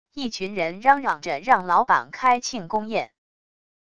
一群人嚷嚷着让老板开庆功宴wav音频